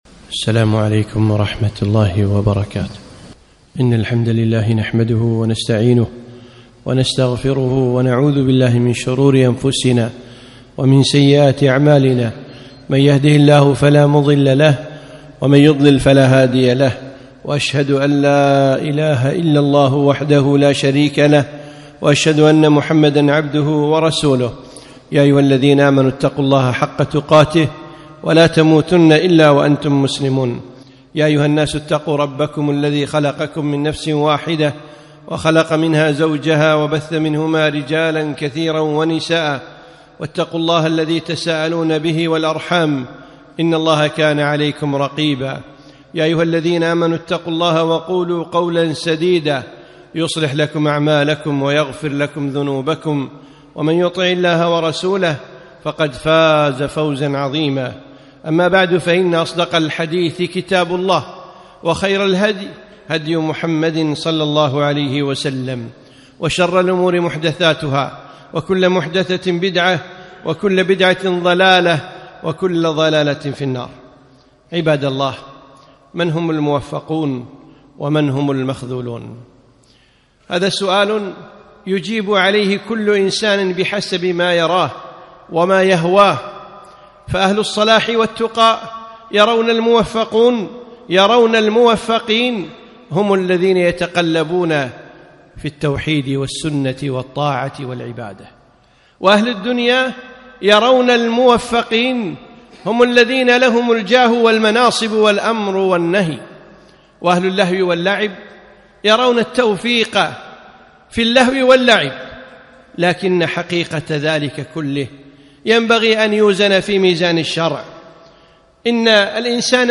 خطبة - بين التوفيق والخذلان